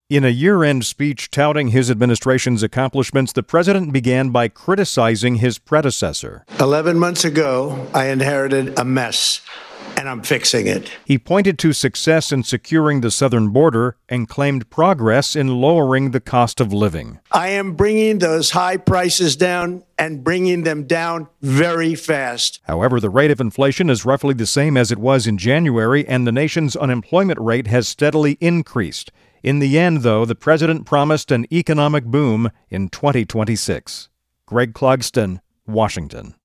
President Trump delivered a rosy review of his first year back in office. In a year-end speech touting his administration’s accomplishments, the president began by criticizing his predecessor for leaving a “mess.”
TRUMP-SPEECH-clug-w1-WEDpm-12-17.mp3